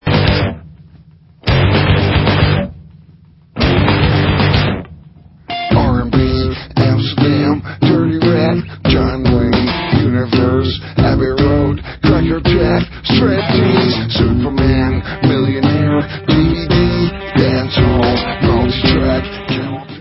Allstar dutch rockband